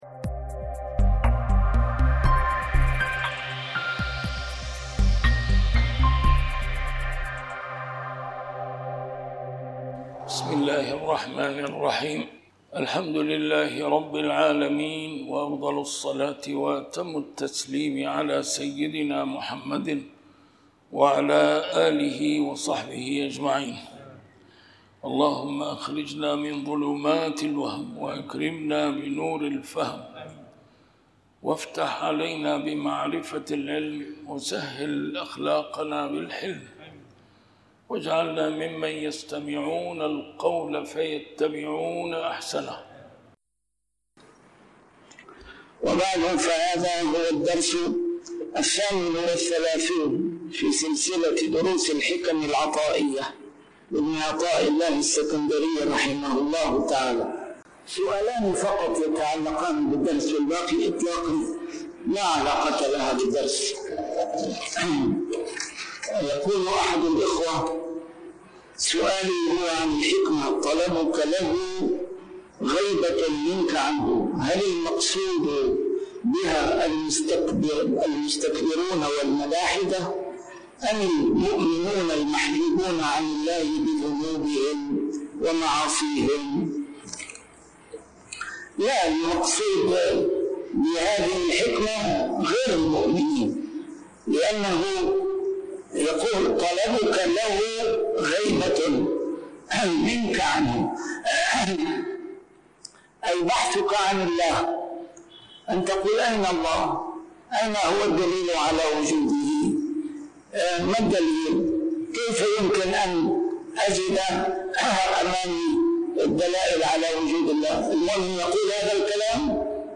A MARTYR SCHOLAR: IMAM MUHAMMAD SAEED RAMADAN AL-BOUTI - الدروس العلمية - شرح الحكم العطائية - الدرس رقم 38 شرح الحكمة 22